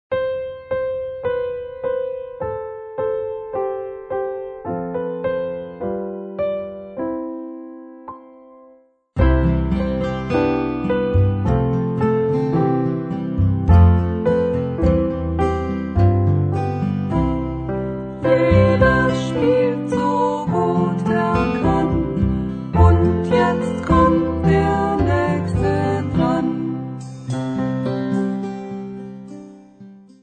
Besetzung: Sopranblockflöte